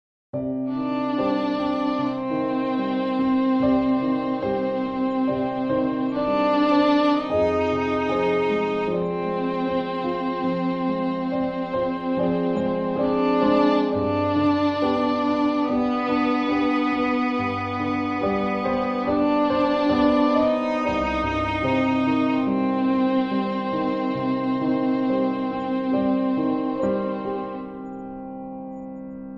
piano_violin-freesound-26340.mp3